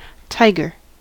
tiger: Wikimedia Commons US English Pronunciations
En-us-tiger.WAV